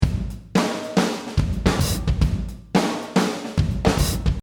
Real drums with the sequenced variety
Before elastic audio
drumsnoQ.mp3